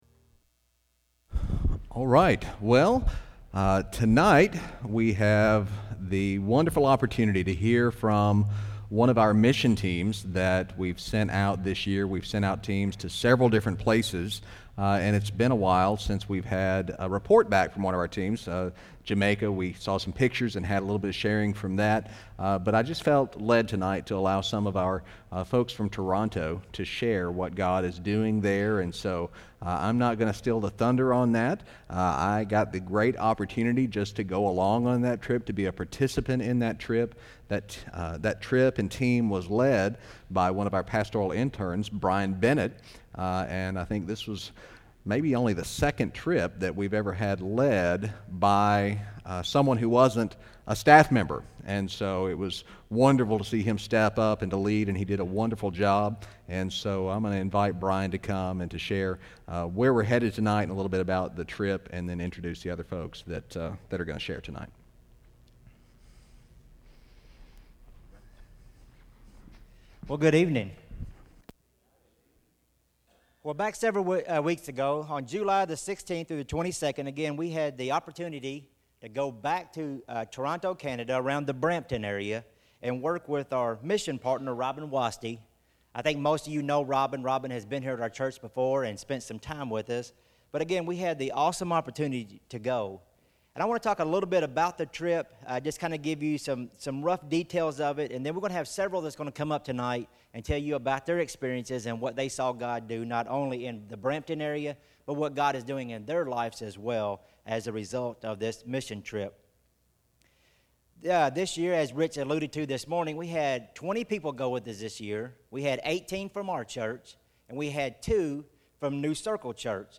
2016 Joshua This is an evening sermon with no manuscript attached.